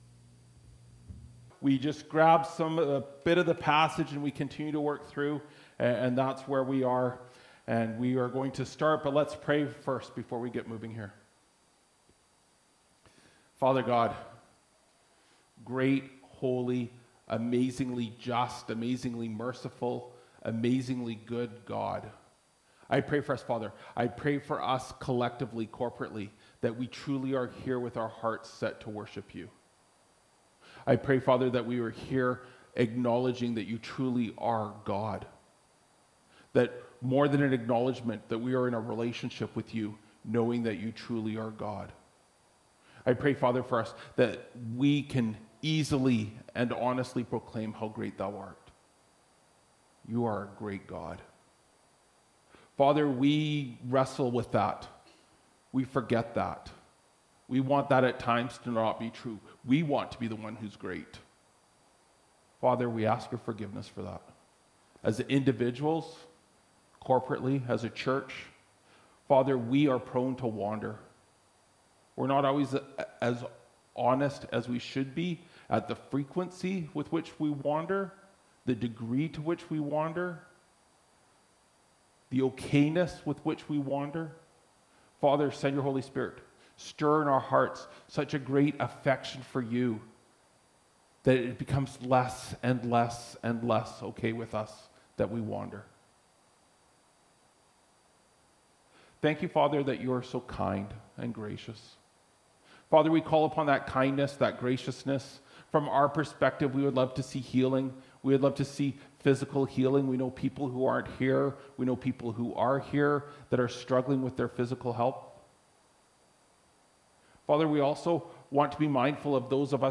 Apr 27, 2025 Leadership in the Local Church – Part 1 (1 Timothy 2:1–3:7) MP3 SUBSCRIBE on iTunes(Podcast) Notes Discussion Sermons in this Series This sermon was recorded in Salmon Arm and preached in both campuses.